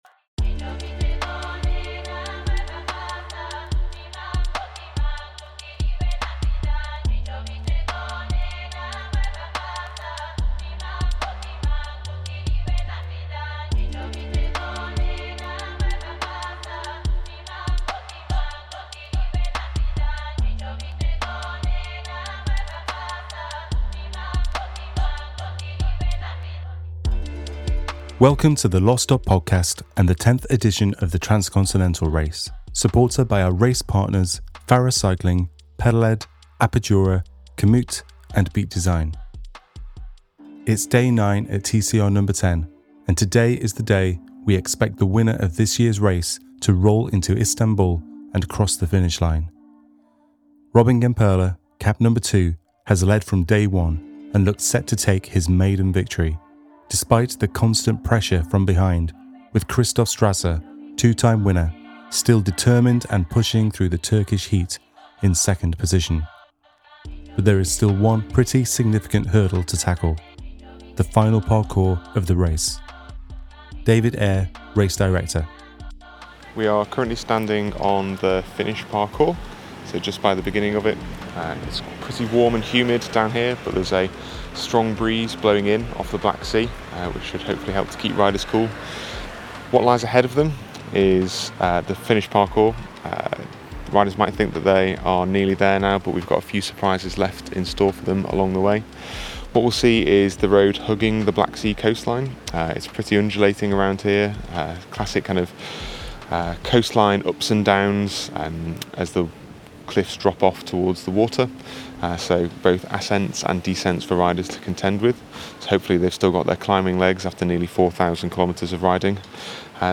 The Race is taking its toll on bodies and minds, and we hear from riders as they engage in a battle of wills to make it to Istanbul. Follow along with the 10th edition of the Transcontinental Race; the self-supported bicycle race across Europe.